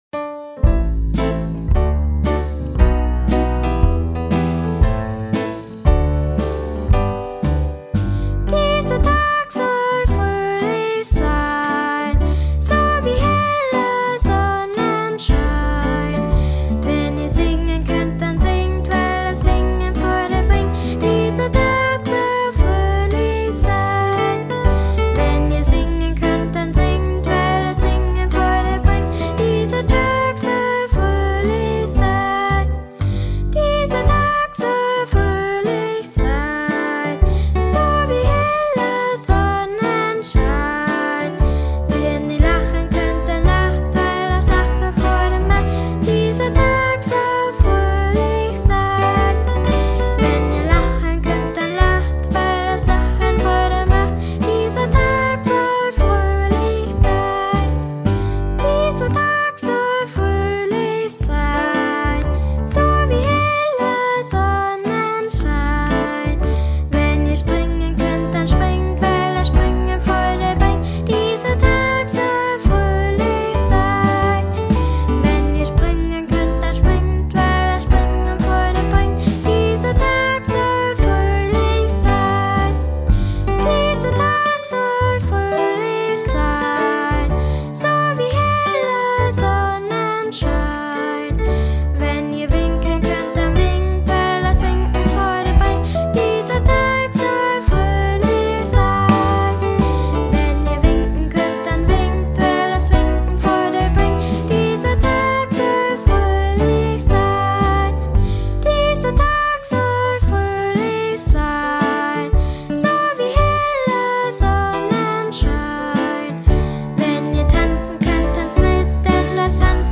Liedeinspielung
DieserTagsollfroehlichsein_voc.mp3